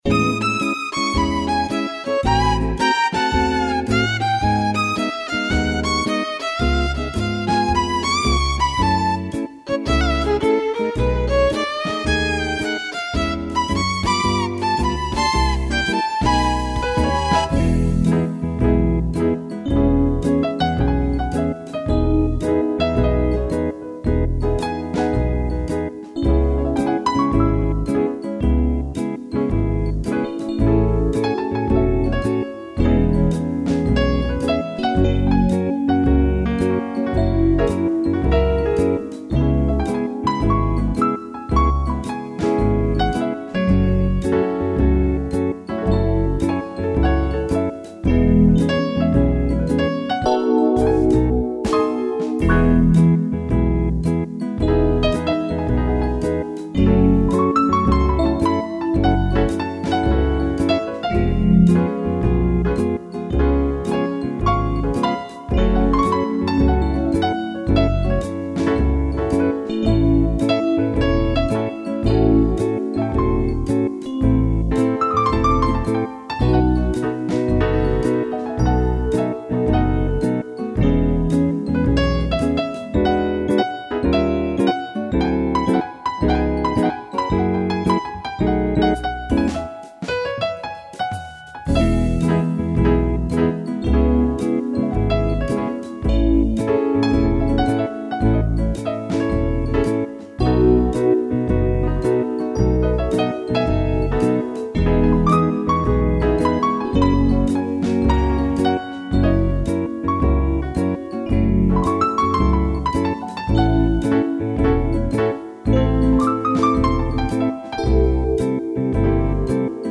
instrumental
piano